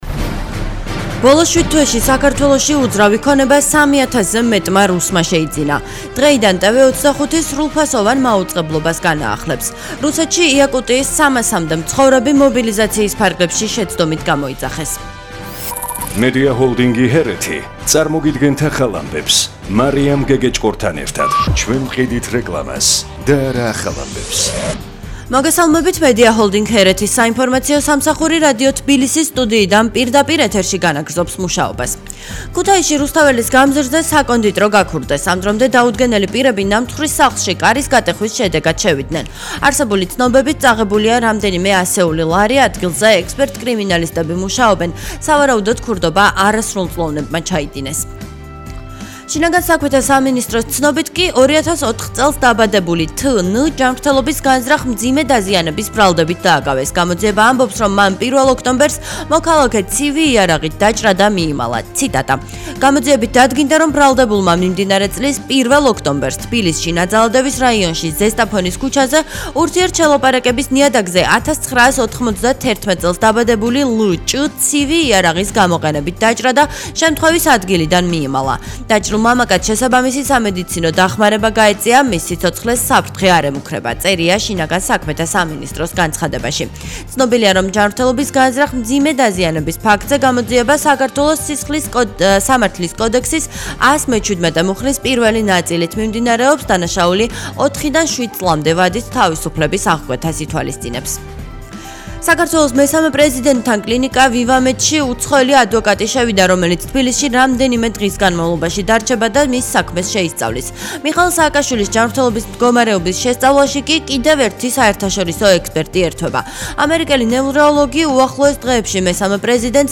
ახალი ამბები 12:00 საათზე